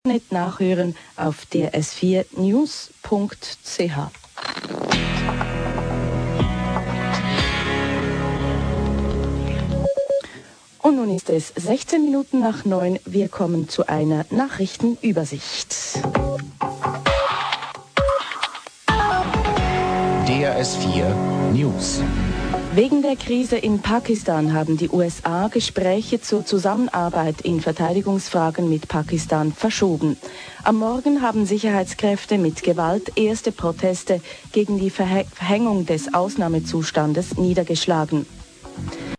Mitschnitt von gerade eben. 45 Kbps!